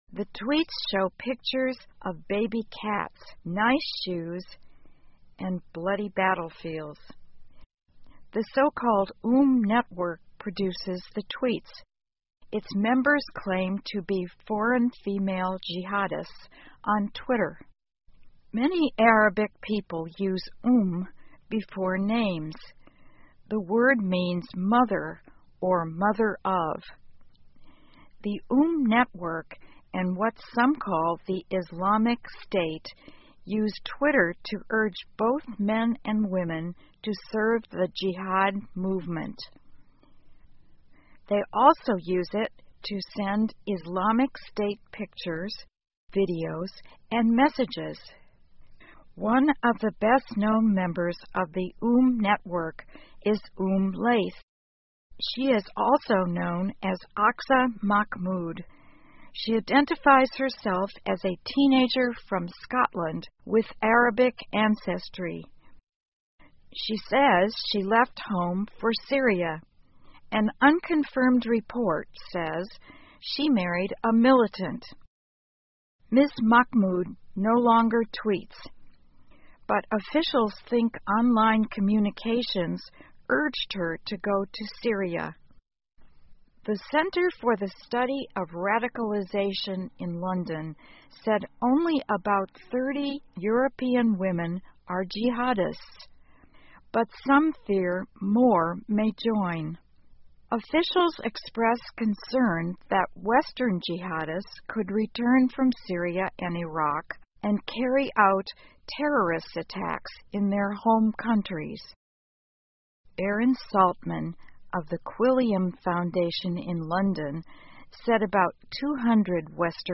VOA慢速英语2014 Women Use Twitter to Support Jihad 听力文件下载—在线英语听力室